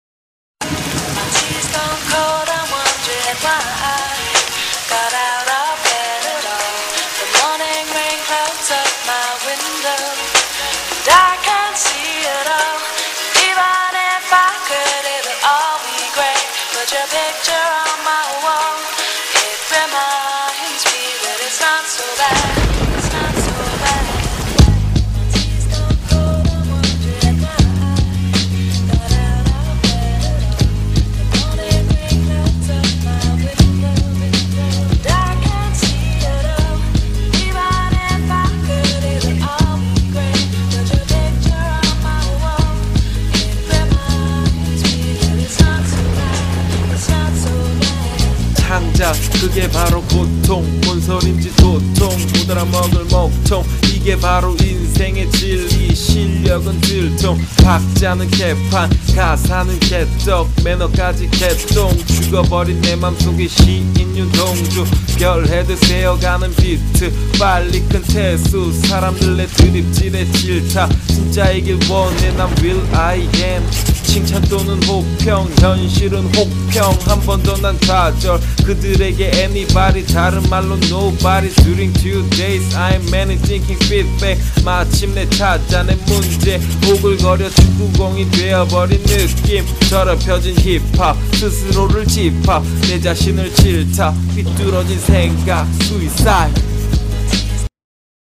46초정도부터 랩이 시작되오니 앞부분이 길다 여기시면 쭈욱~ 땡겨 들으셔도 좋으실것 같습니다!!